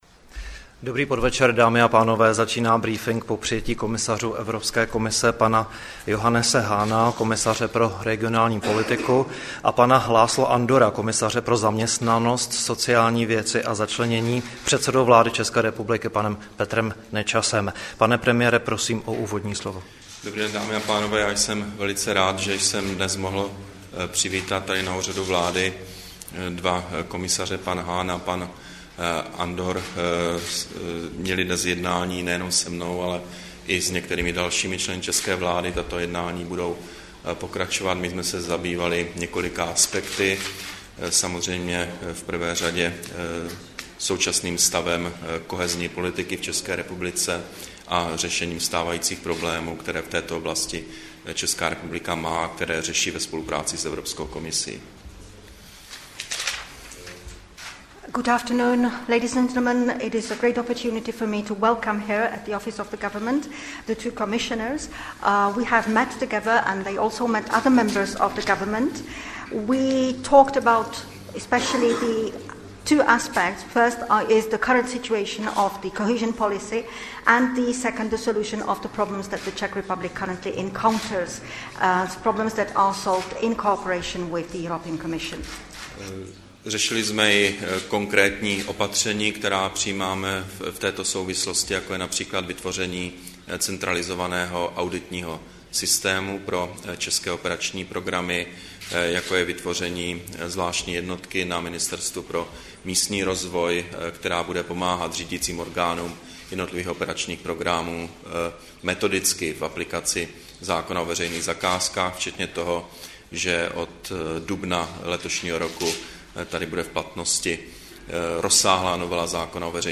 Brífink po přijetí komisařů Evropské komise, 22. března 2012